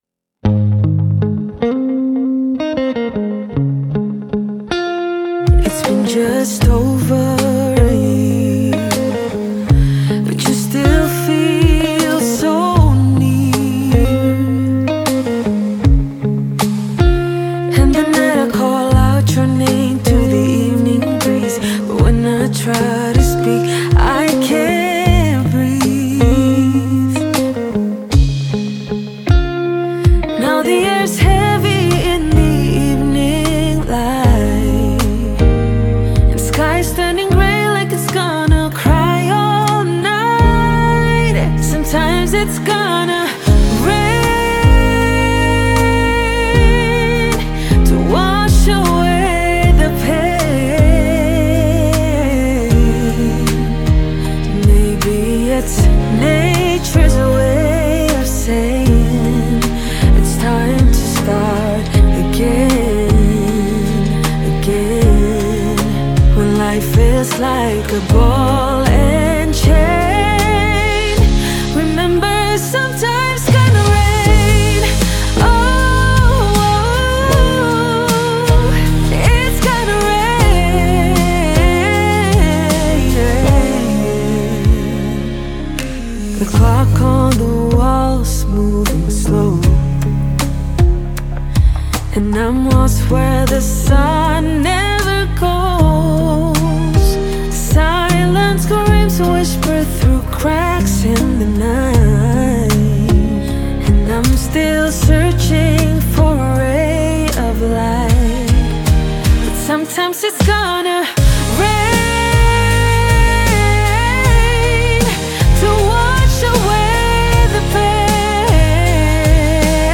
"Rain" (country)